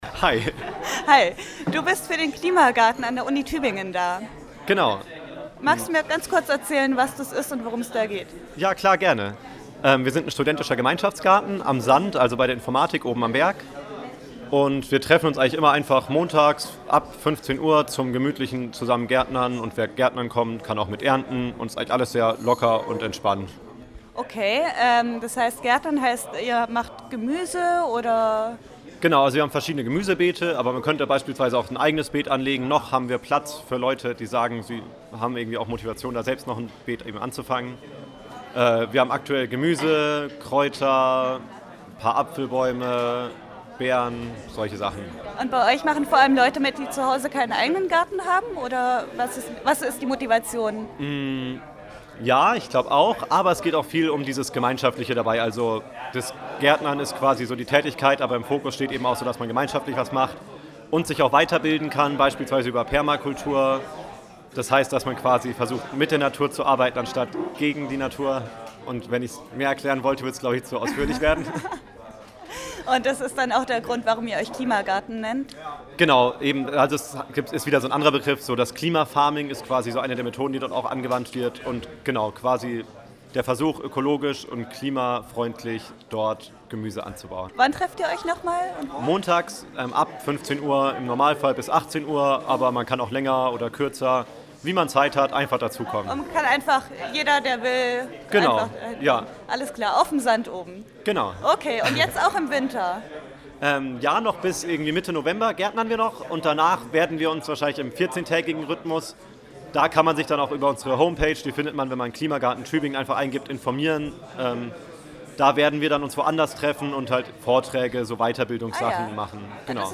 Hier findet ihr einige kurze Interviews und Vorstellungen von Gruppen, die sich am 20.10. auf dem Markt der möglichen Alternativen präsentiert haben.
Klimagarten Kurzinterview
67066_Klimagarten_Kurzinterview.mp3